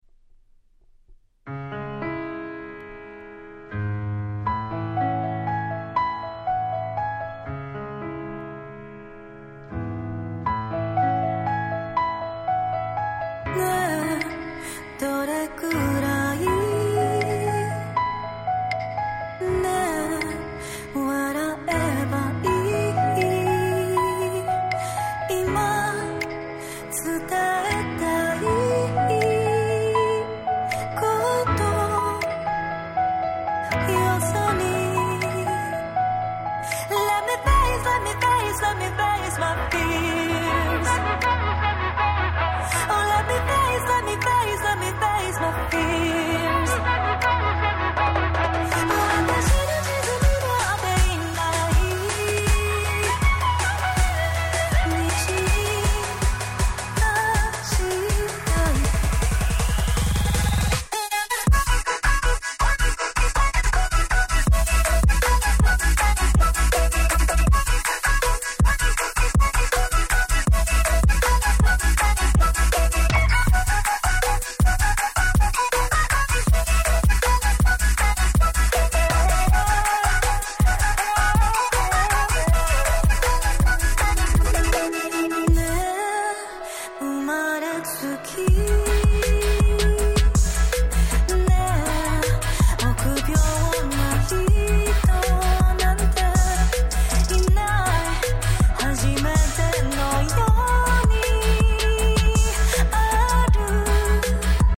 19' Smash Hit Japanese R&B !!